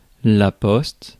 Ääntäminen
IPA : /pəˈzɪʃən/